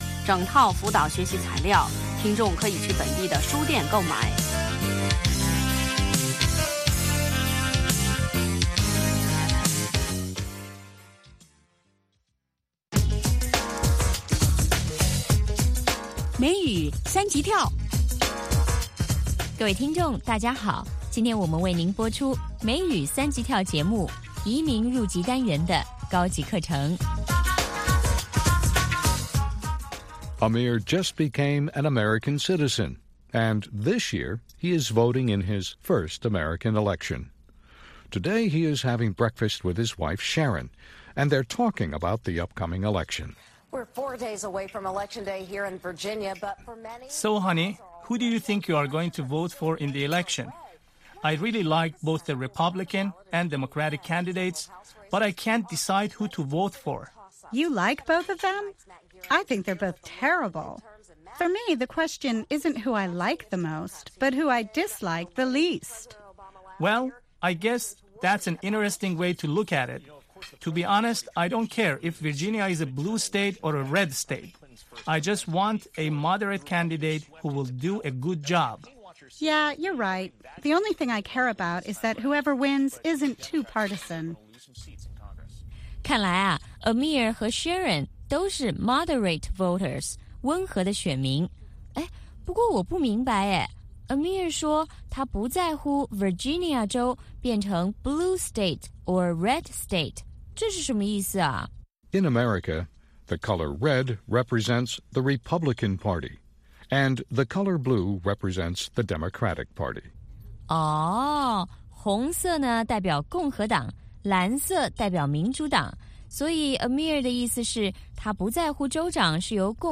北京时间下午5-6点广播节目。